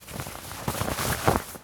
foley_cloth_light_fast_movement_04.wav